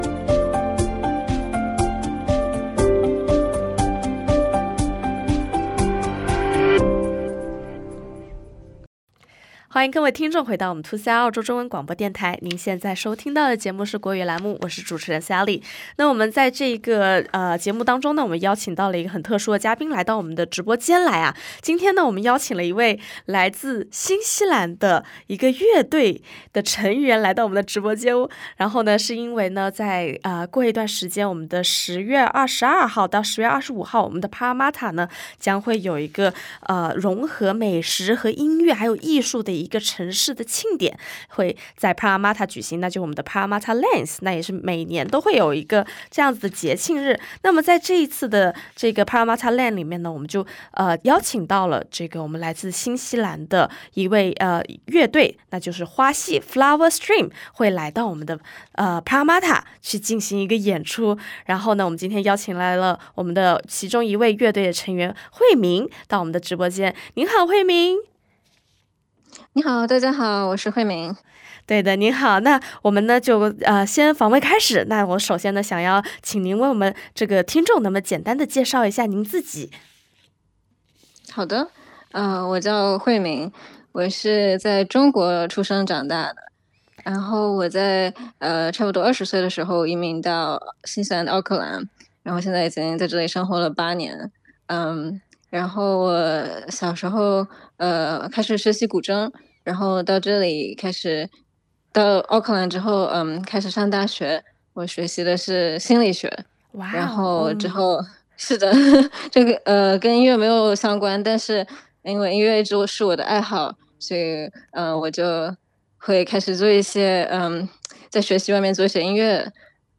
访问录音： https
Flowerstream-Interview-Mixdown.mp3